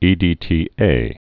(ēdē-tē-ā)